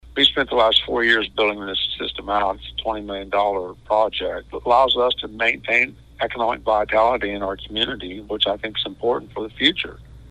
CLICK HERE to listen to details from City Manager Craig Stephenson.